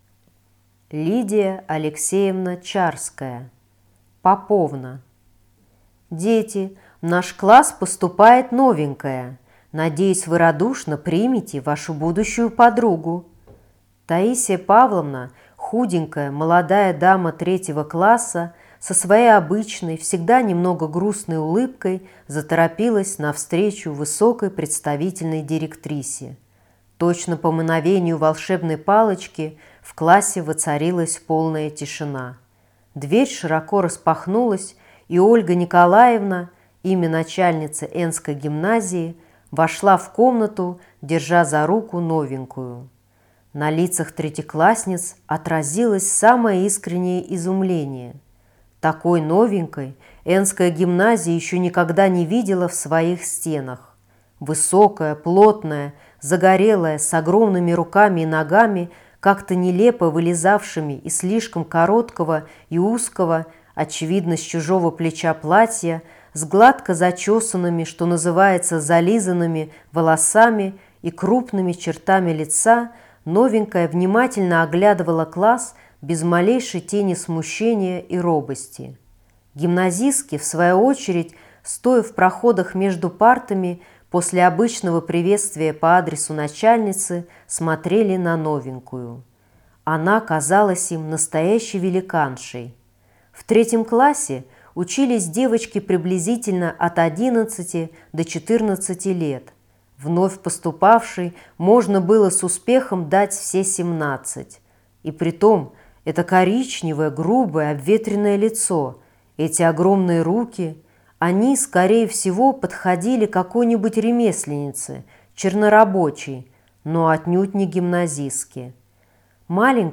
Аудиокнига Поповна | Библиотека аудиокниг